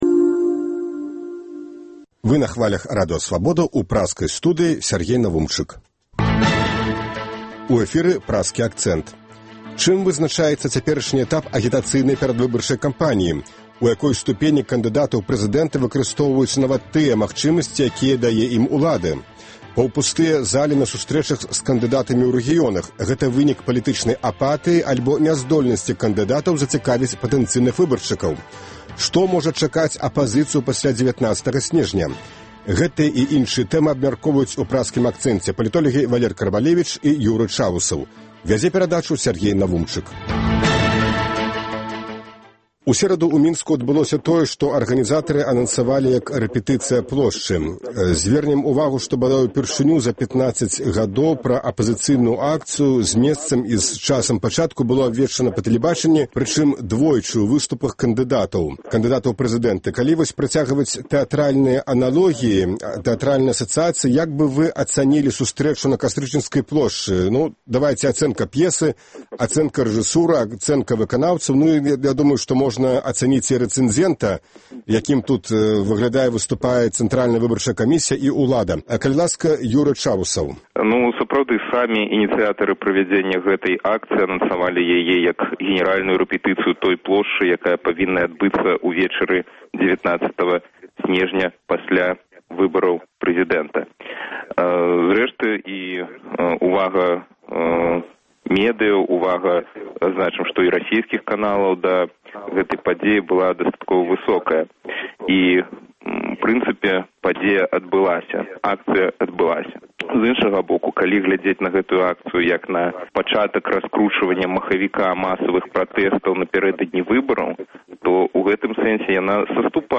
Што можа чакаць апазыцыю пасьля 19-га сьнежня? Гэтыя і іншыя тэмы абмяркоўваюць палітолягі